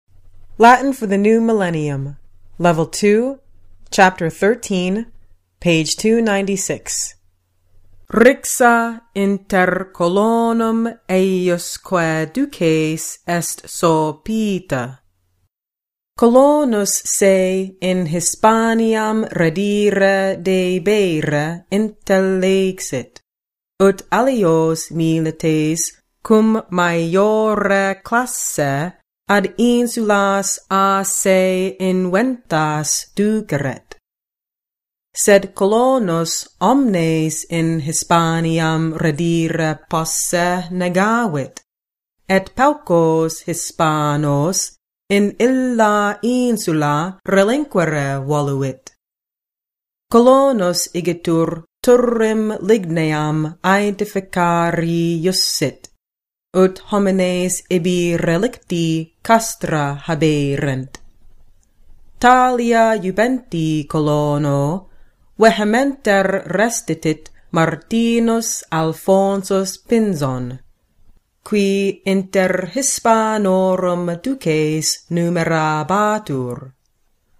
provide a professionally recorded reading in the restored classical pronunciation of Latin.